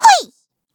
Taily-Vox_Attack2_kr.wav